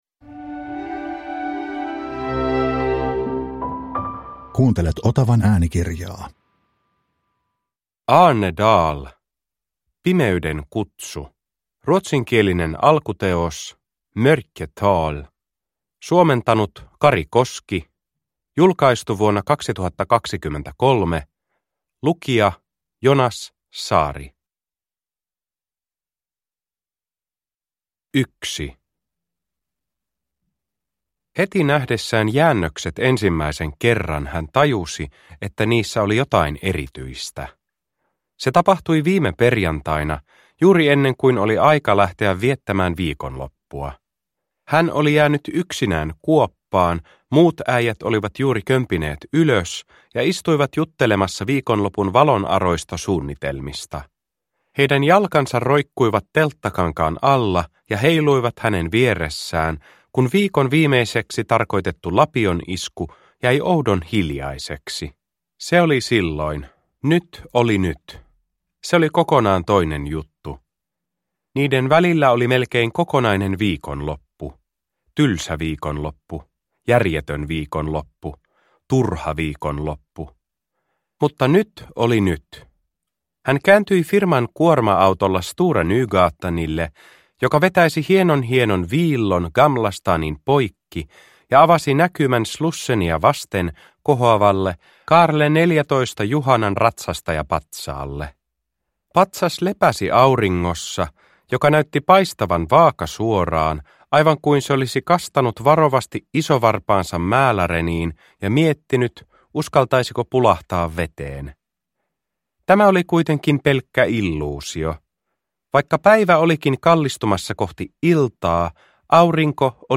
Pimeyden kutsu – Ljudbok – Laddas ner